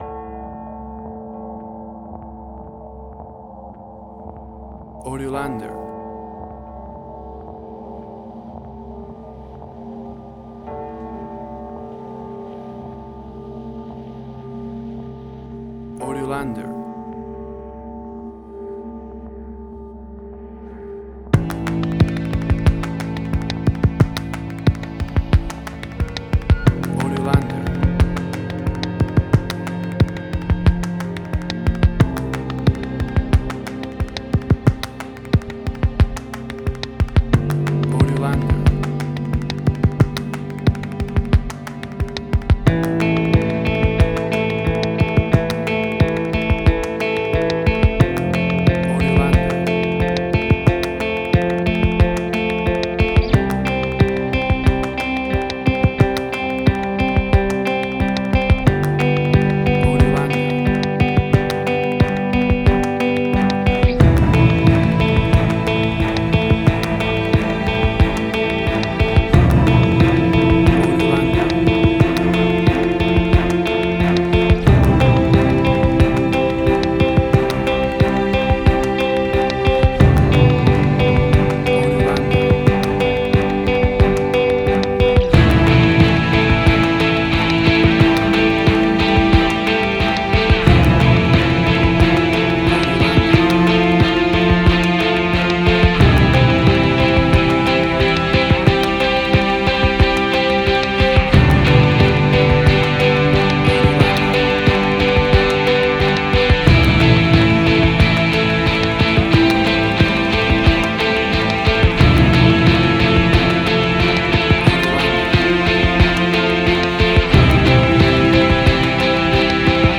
Post-Rock.
Tempo (BPM): 90